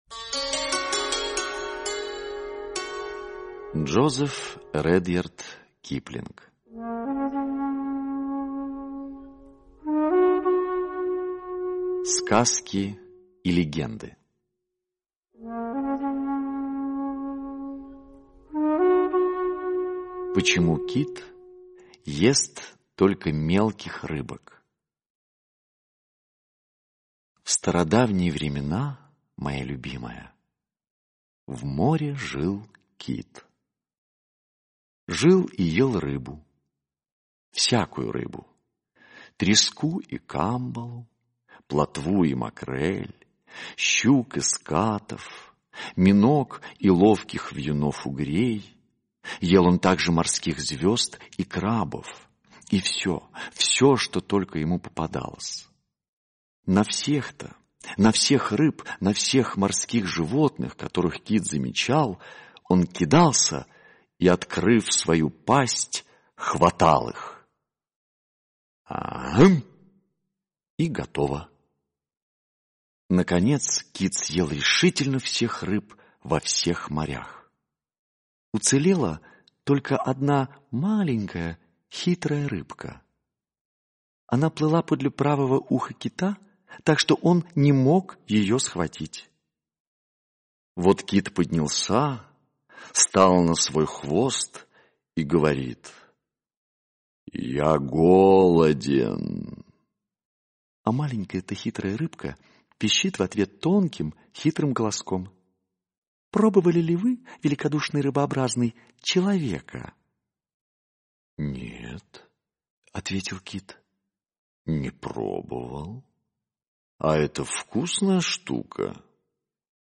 Почему кит ест только мелких рыбок - аудиосказка Киплинга - слушать